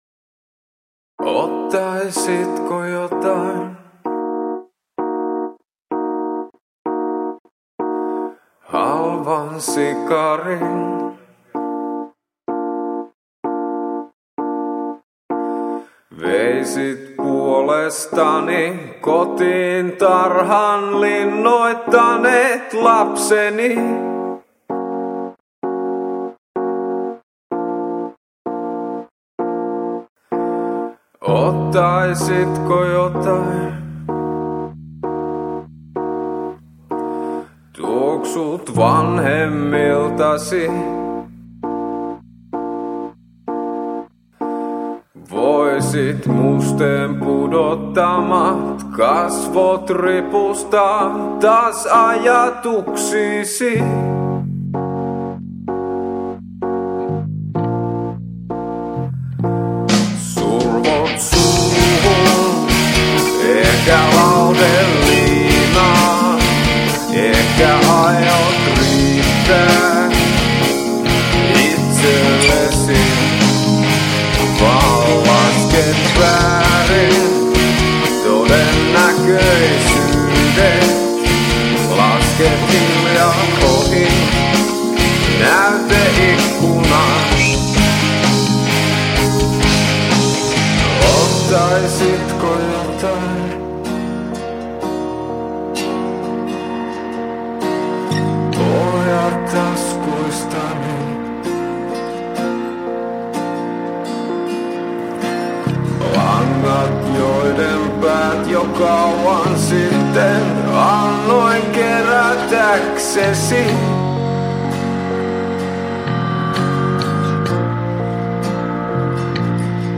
rock-yhtye
AO linkit ovat kaikki 128 kbps stereo mp3-tiedostoja.
Onnistunut fiilistelypala.